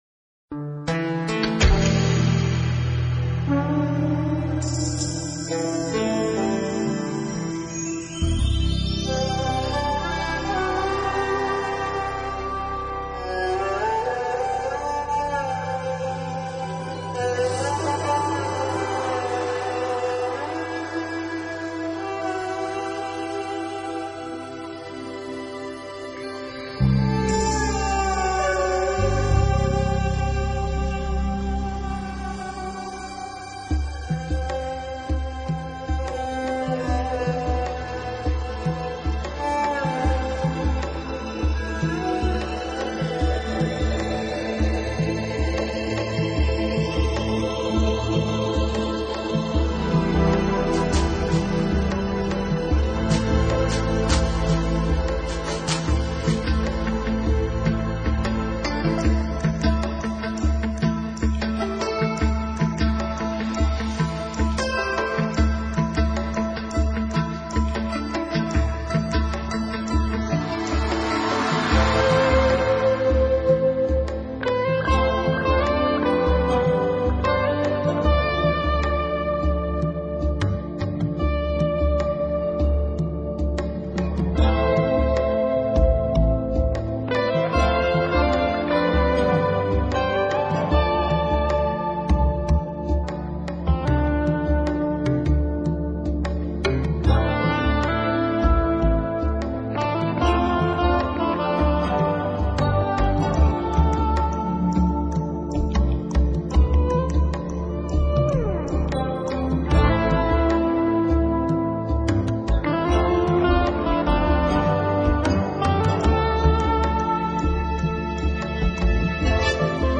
类型：NEWAGE